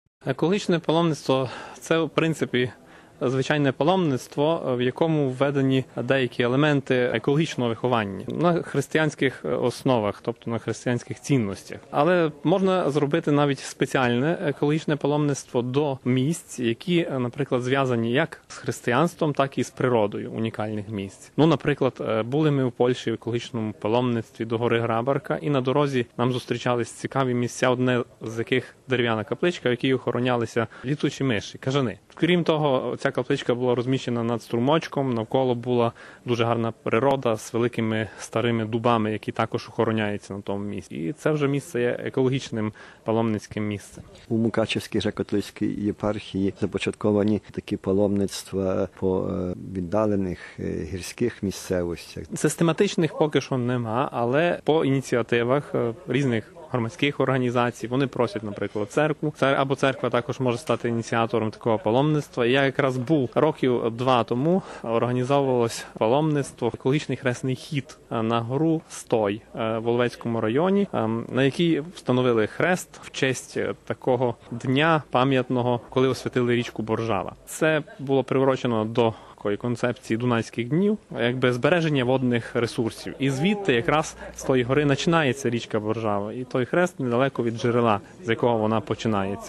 Cikava nauka - Інтерв"ю